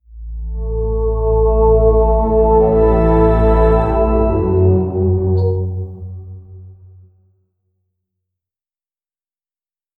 The sound of good karma or moral for deeds and the sound of bad karma.